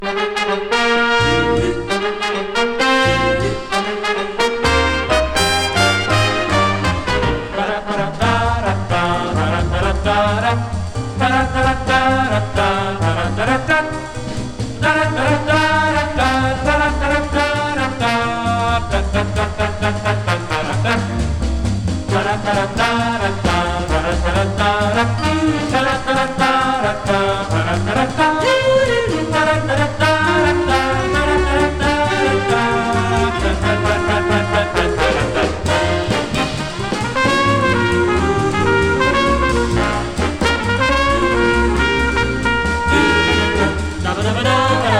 スウィング感と洒落たアレンジも魅力たっぷり。
Jazz, Stage & Screen　USA　12inchレコード　33rpm　Mono